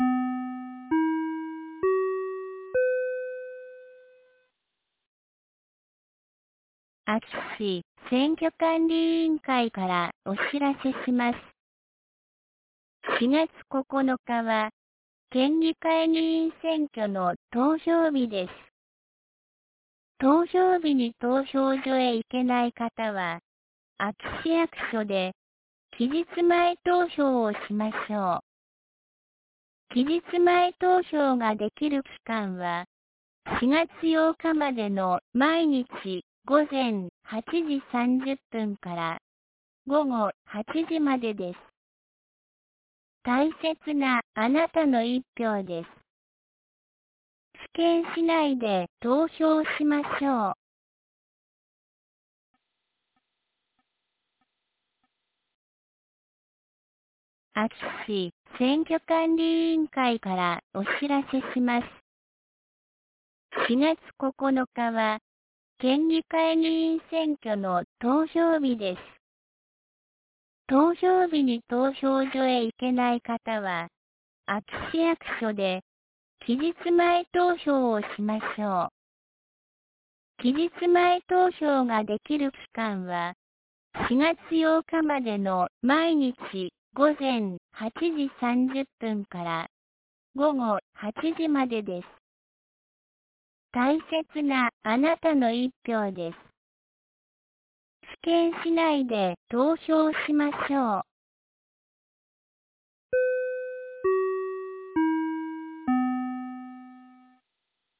2023年04月01日 17時11分に、安芸市より全地区へ放送がありました。